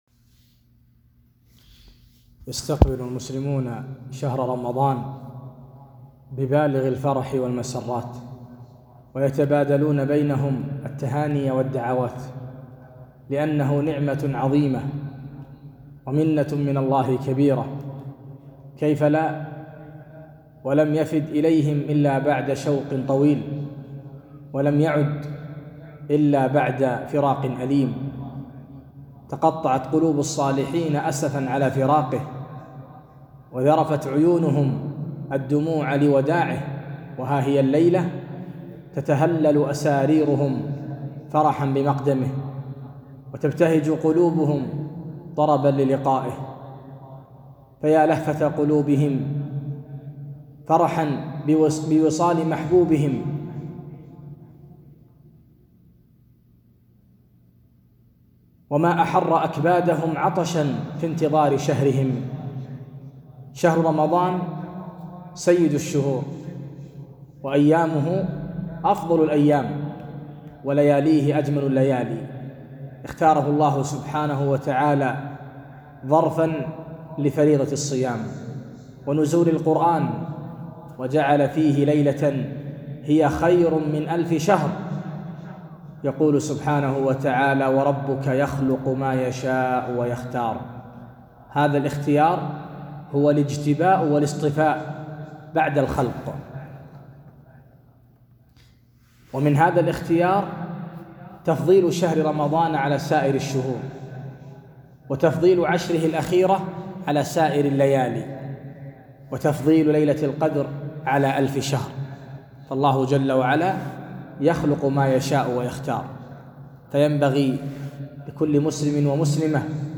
استقبال شهر رمضان - كلمة